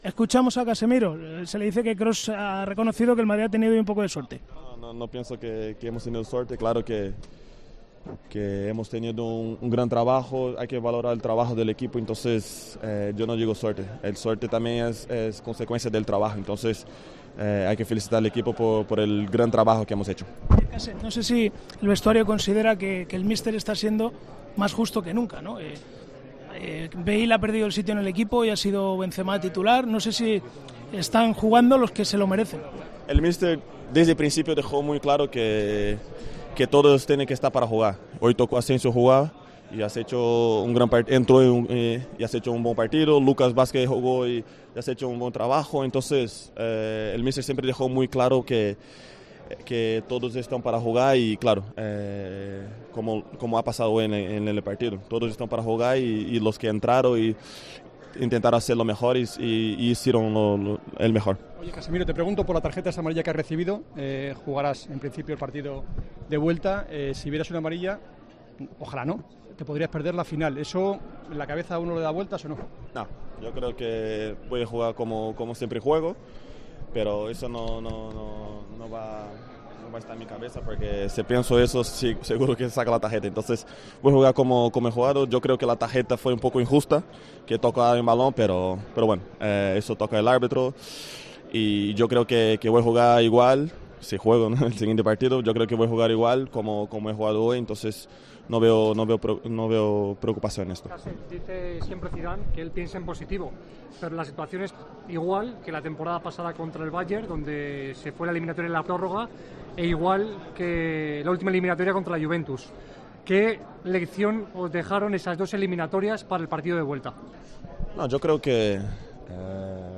"Sabemos que va a ser un partido muy duro porque la eliminatoria no está hecha. Nos va a tocar saber sufrir en casa pero tendremos el apoyo de nuestra afición en una semifinal muy importante. Nos jugamos nuestro año y esperamos estar en la final", dijo en la zona mixta del Allianz Arena.